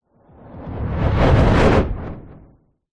На этой странице собраны звуки ударной волны после взрыва — от глухих ударов до резких перепадов давления.
Звук воздушного порыва перед ударом, разрыв атмосферы, мощная ударная волна, угрожающая близость